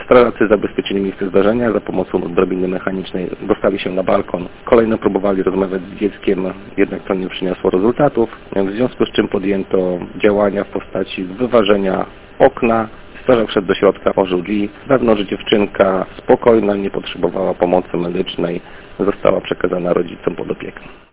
z ełckiej straży pożarnej